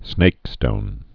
(snākstōn)